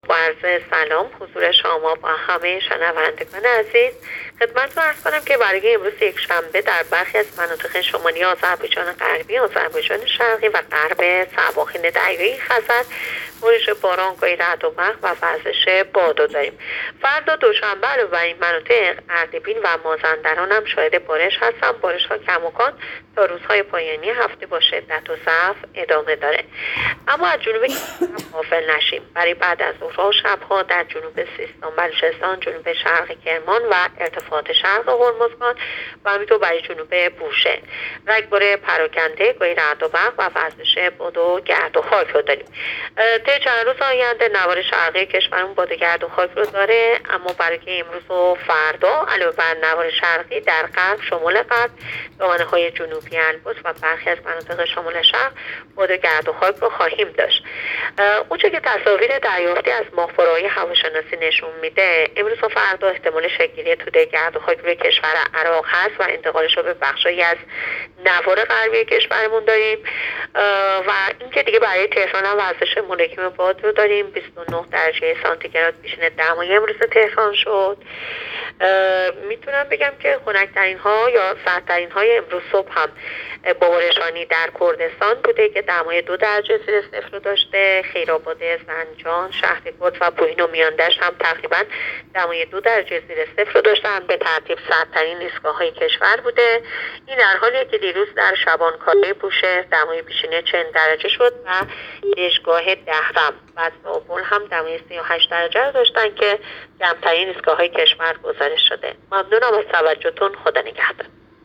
گزارش رادیو اینترنتی پایگاه‌ خبری از آخرین وضعیت آب‌وهوای ۲۰ مهر؛